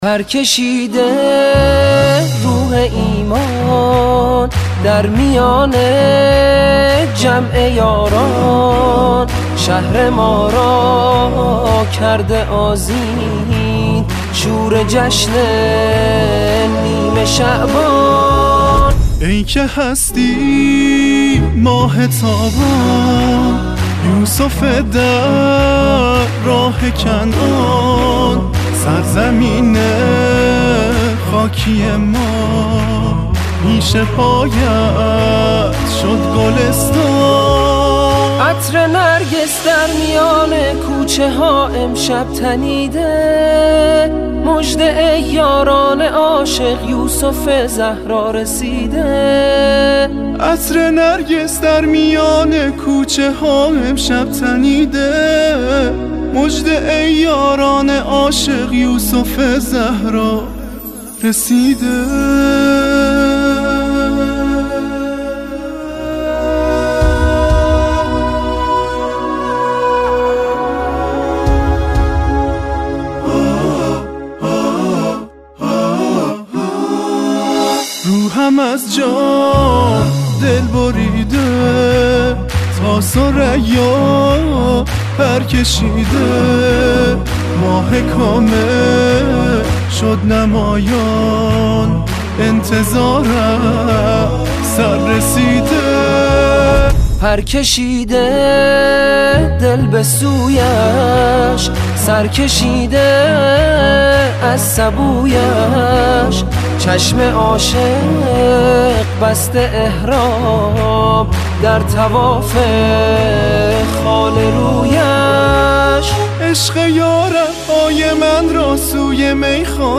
نماهنگ سرود